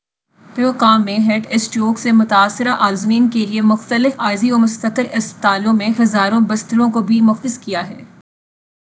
deepfake_detection_dataset_urdu / Spoofed_TTS /Speaker_04 /15.wav